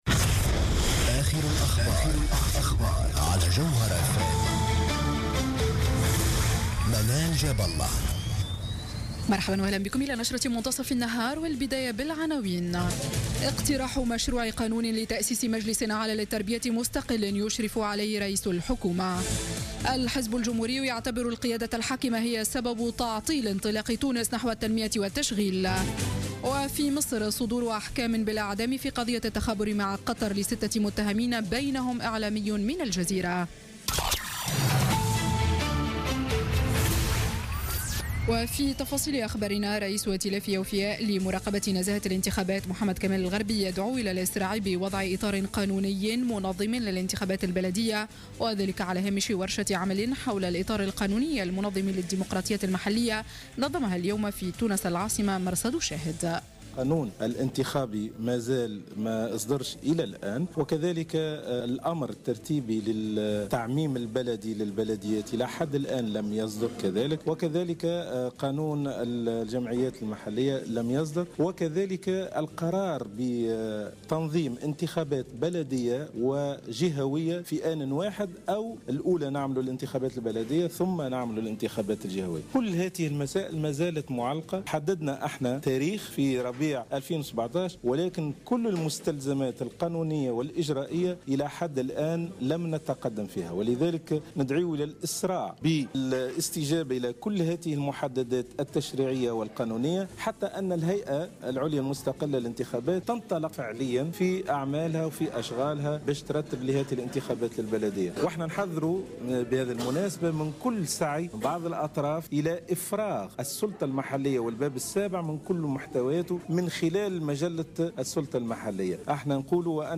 نشرة أخبار منتصف النهار ليوم السبت 7 ماي 2016